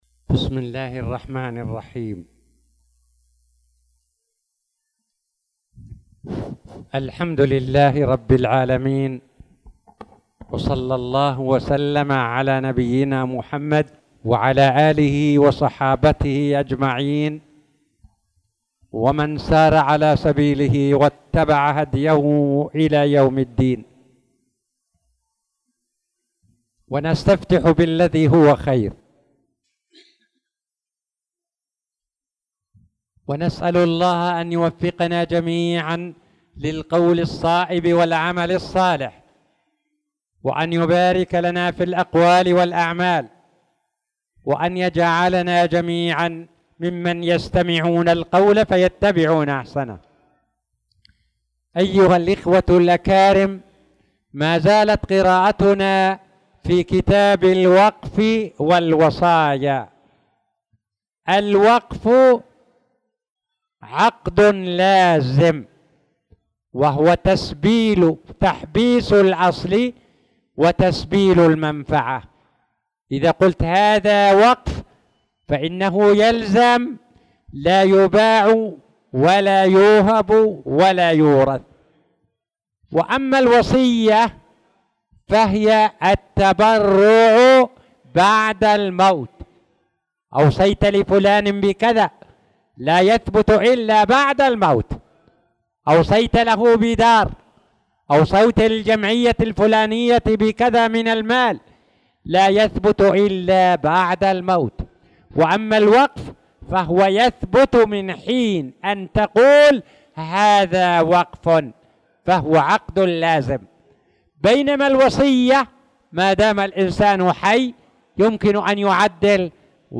تاريخ النشر ٨ شعبان ١٤٣٧ هـ المكان: المسجد الحرام الشيخ